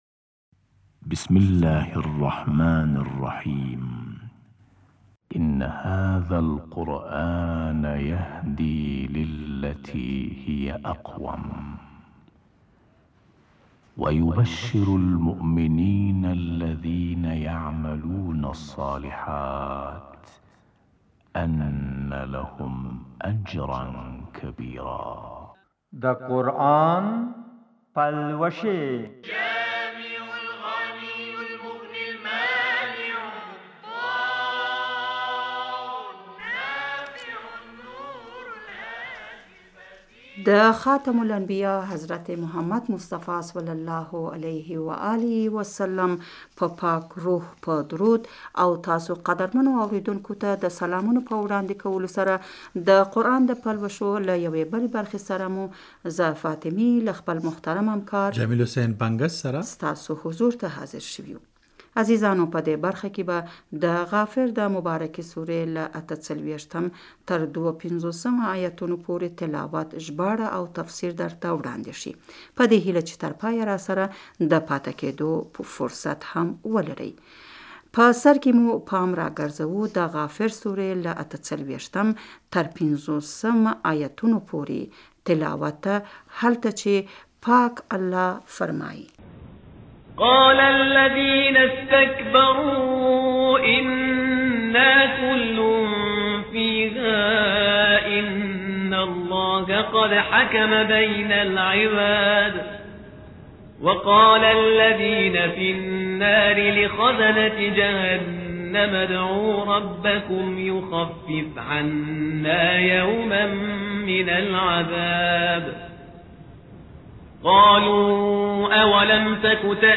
د غافر سورې له 48تر 52پورې د آیتونو ترجمه ،تفسیر او تلاؤت